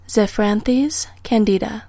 Pronounciation:
Zef-a-RAN-thes can-DEE-da